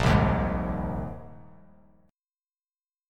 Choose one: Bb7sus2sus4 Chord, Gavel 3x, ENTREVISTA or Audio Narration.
Bb7sus2sus4 Chord